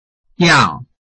臺灣客語拼音學習網-客語聽讀拼-饒平腔-開尾韻
拼音查詢：【饒平腔】giau ~請點選不同聲調拼音聽聽看!(例字漢字部分屬參考性質)